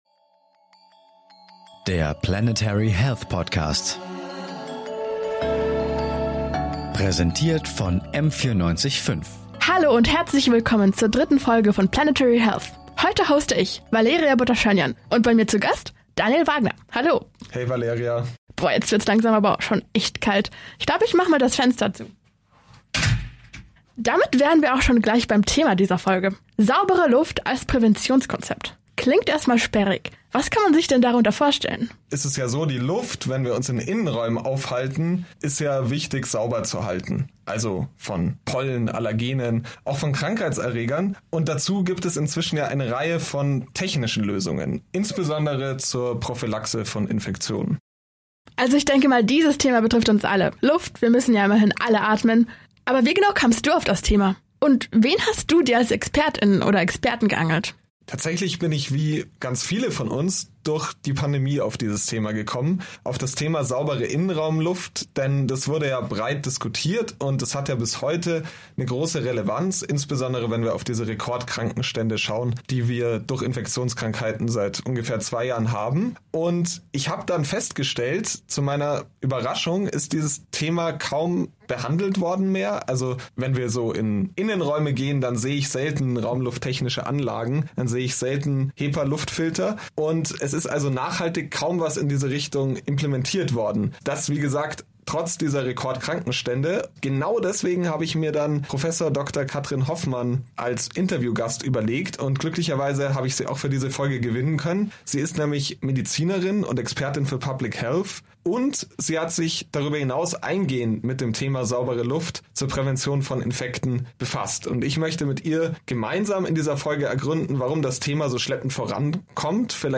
Als Medizinerin und Public Health-Expertin hat sie sich eingehend mit Konzepten für saubere Luft in Innenräumen befasst. Dabei klären wir auch, warum das Thema nicht nur für die Öffentliche Gesundheit, sondern auch auf Ebene der Planetary Health relevant ist.